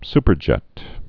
(spər-jĕt)